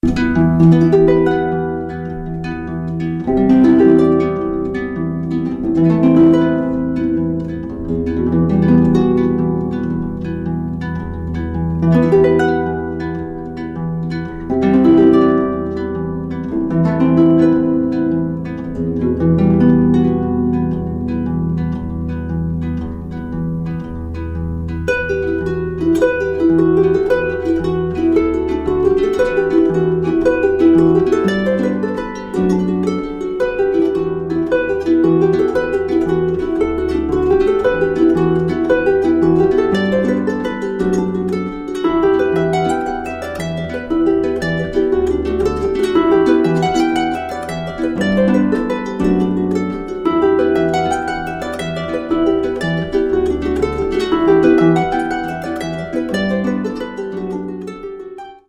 keltische Musik und eigene Kompositionen